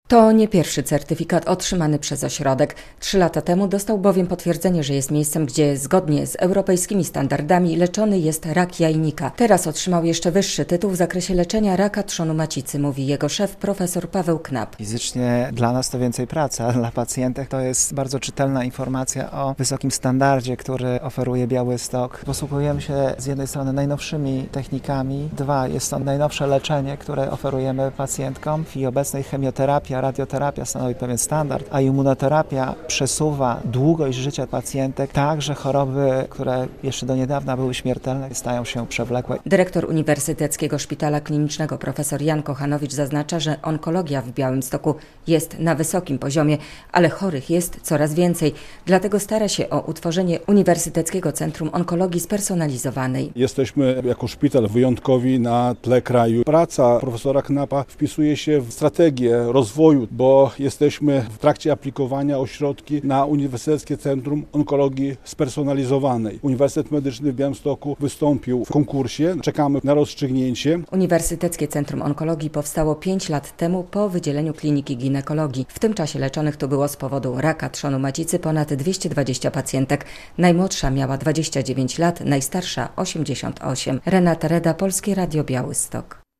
UCO w Białymstoku dostało prestiżowy europejski certyfikat - relacja